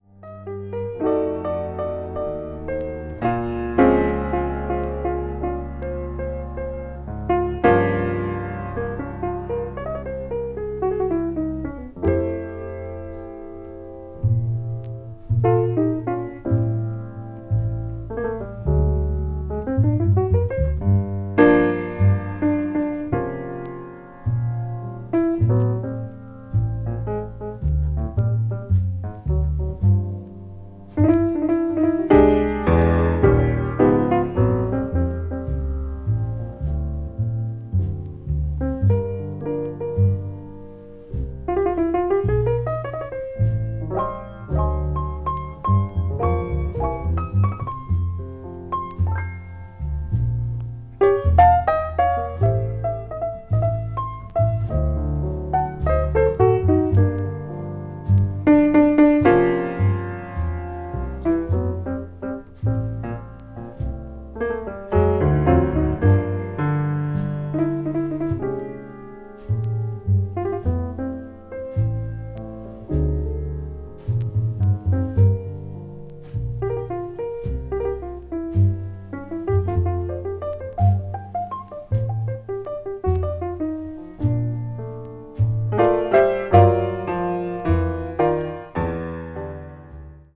media : EX/EX(some slightly noises.)
bass
drums
bop   modern jazz   piano trio